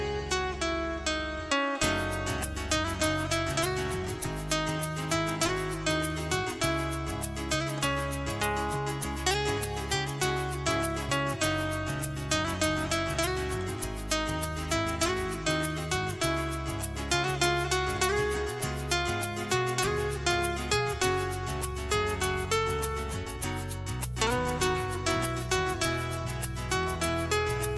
Category: iPhone Ringtones